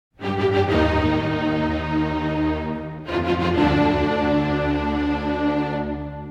Звуки тревоги
В коллекции — резкие сирены, нарастающие гудки, тревожные оповещения и другие эффекты, усиливающие напряжение.
Классический звук тревожности, попался